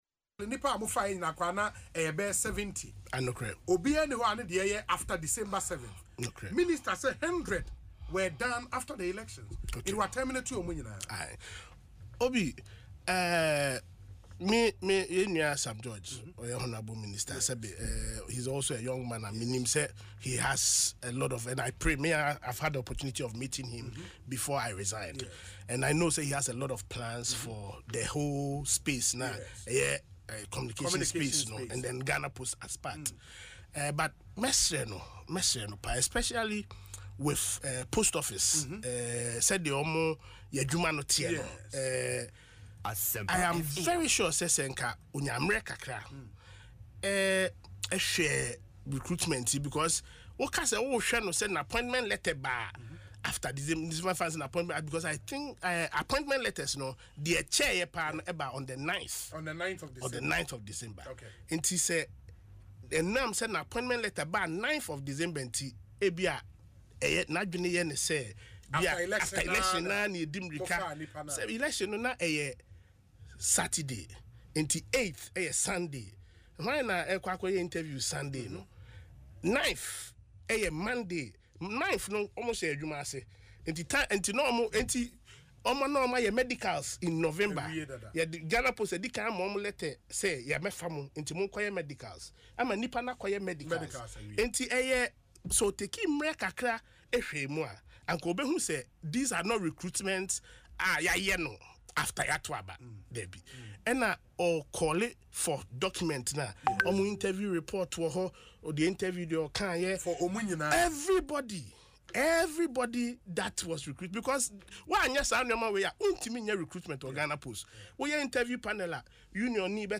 However, speaking on Asempa FM’s Ekosii Sen, Obour explained that the delay should not be misconstrued as a last-minute recruitment scheme.